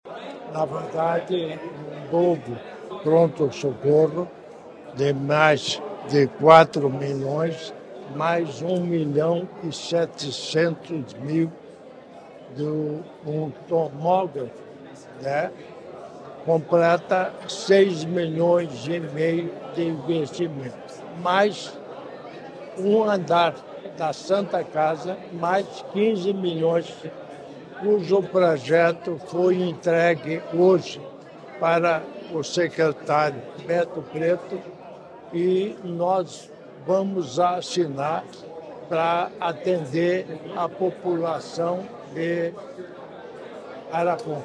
Sonora do vice-governador Darci Piana sobre o novo pronto-socorro da Santa Casa de Arapongas